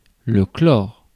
Ääntäminen
Synonyymit centaurée jaune Ääntäminen Tuntematon aksentti: IPA: /klɔʁ/ Haettu sana löytyi näillä lähdekielillä: ranska Käännös Konteksti Substantiivit 1. хлор {m} (hlor) kemia Suku: m .